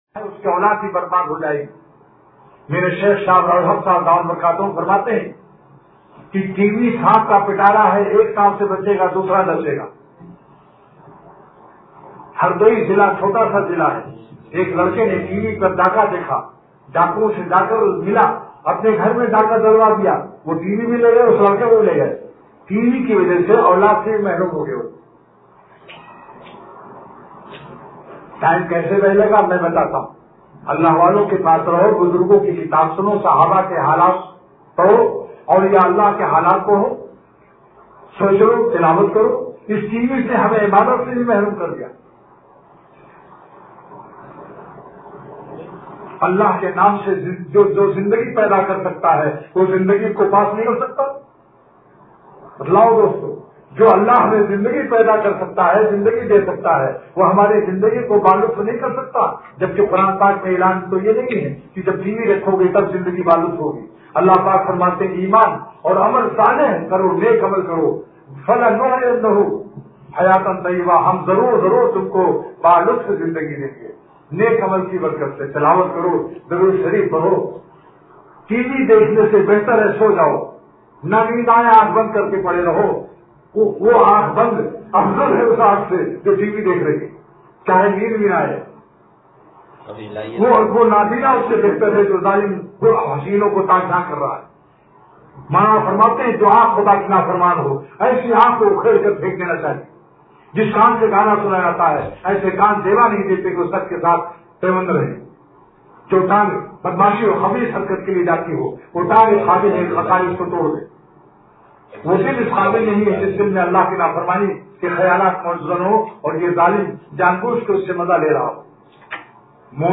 بیان حضرت والا رحمۃ اللہ علیہ – مناجات – ایسی صورت جو مجھے آپ سے غافل کر دے – اتوار